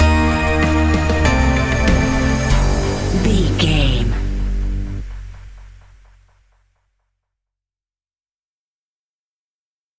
A great piece of royalty free music
Aeolian/Minor
ominous
dark
haunting
eerie
synthesiser
tense
ticking
electronic music